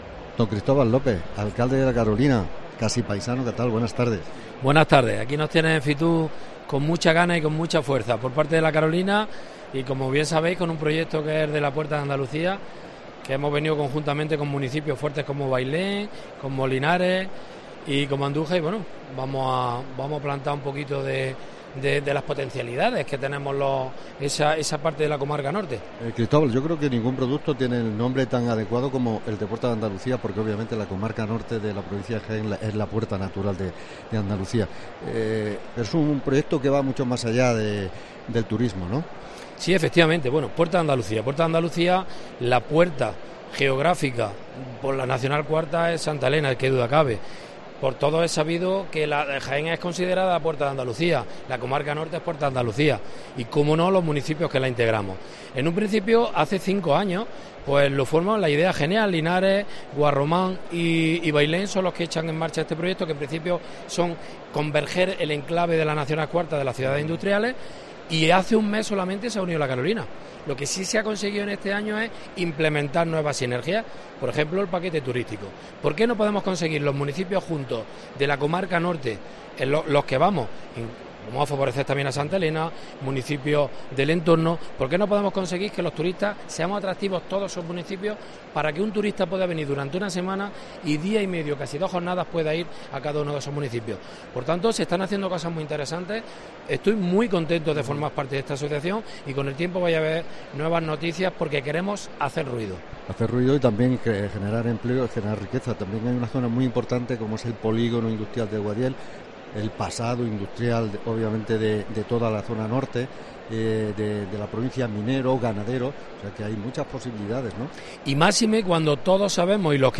Hablamos con el alcalde de La Carolina de este proyecto que se ha presentado en FITUR 2024
Entrevista con Cristobal López, alcalde de La Carolina